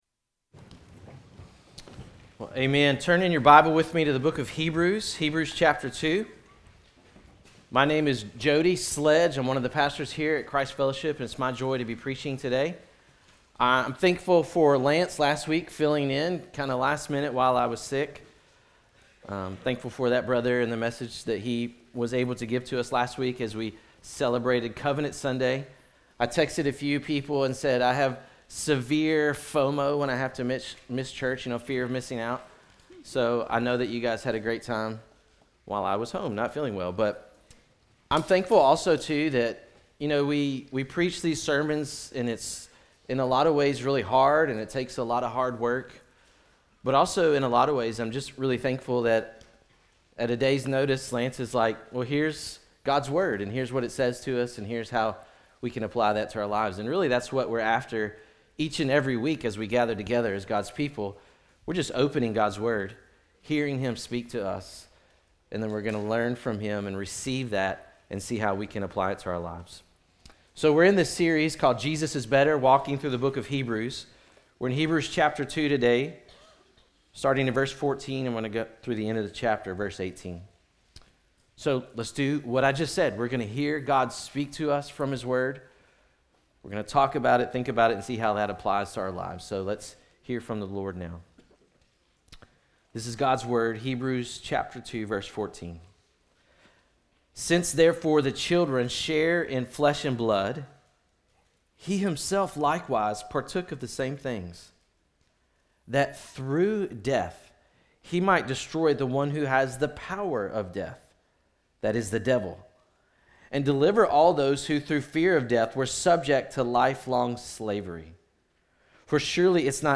All Of Christ For All Of Life Christ Fellowship Sermons podcast